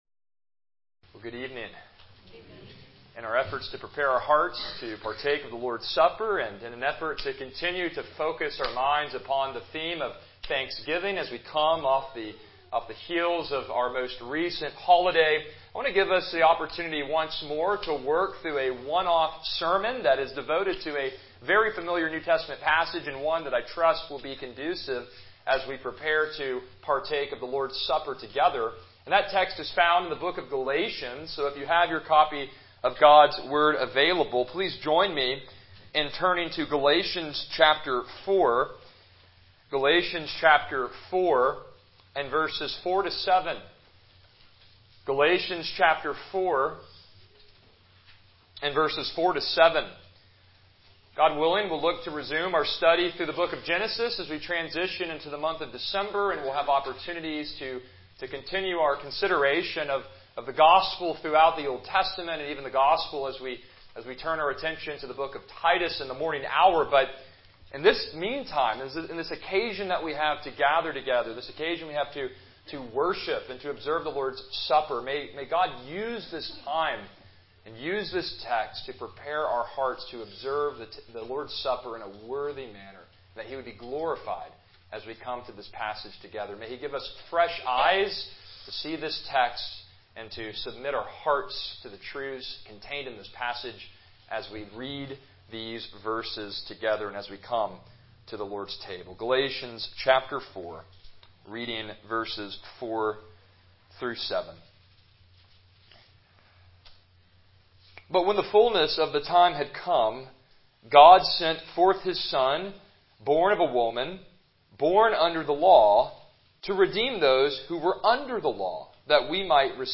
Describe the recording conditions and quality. Passage: Galatians 4:4-7 Service Type: Evening Worship « Be Thankful For the Character of God Medieval Philosophy